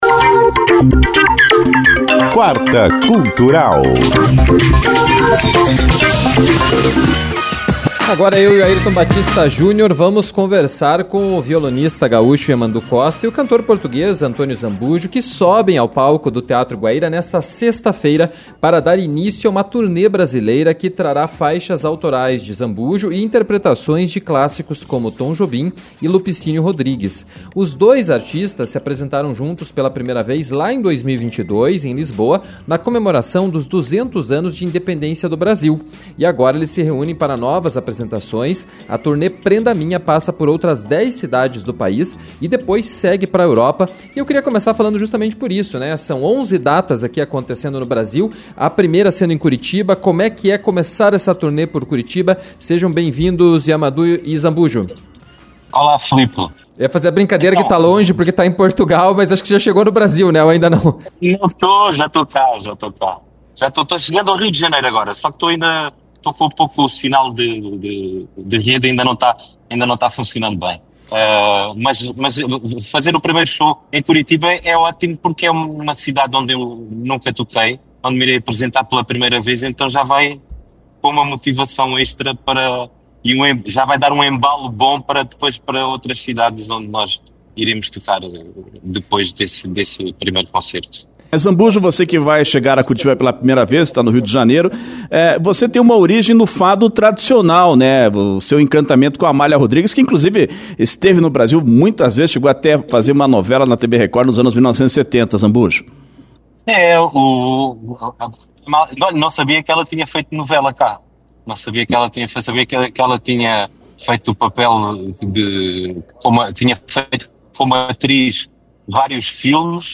conversaram com António Zambujo.